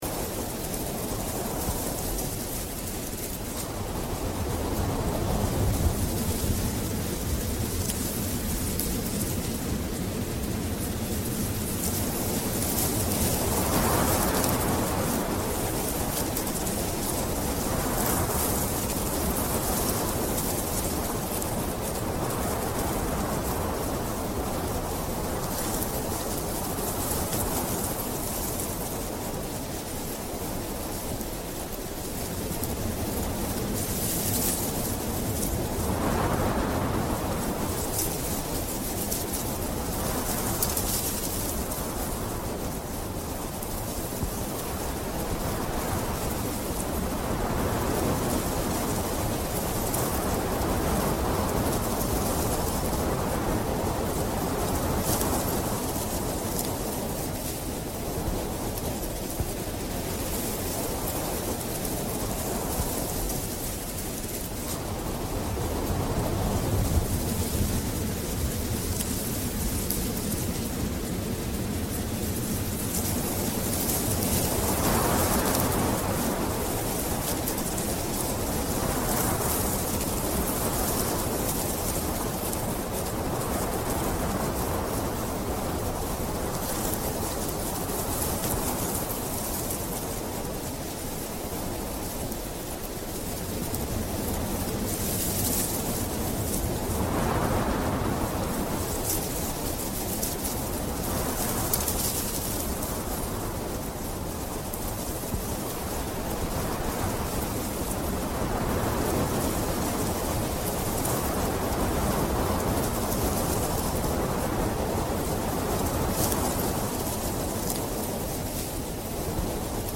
Rain on Window: Cozy Night Sounds That Trigger Sleepiness (1 Hour)
Before you press play, you should know this: all advertisements for Rain Sounds, Rain To Sleep, Rainy Day, Raining Forest, Rainy Noise are placed gently at the very beginning of each episode.